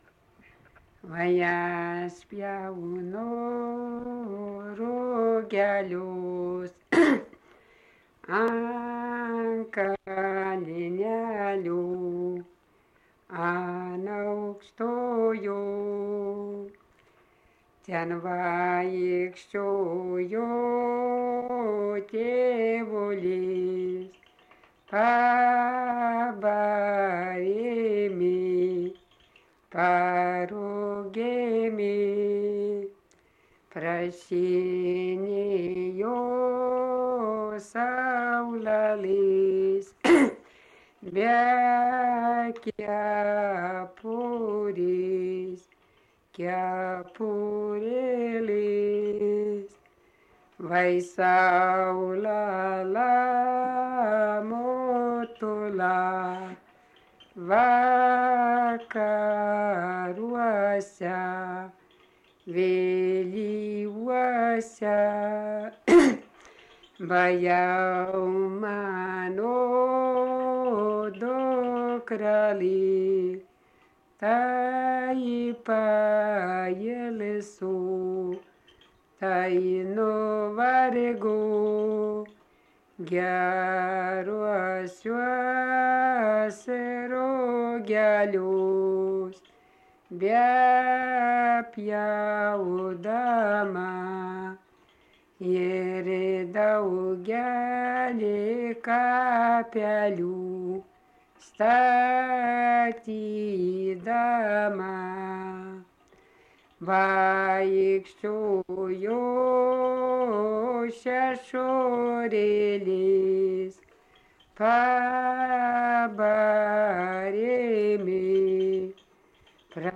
daina, kalendorinių apeigų ir darbo
Erdvinė aprėptis Mardasavas
Atlikimo pubūdis vokalinis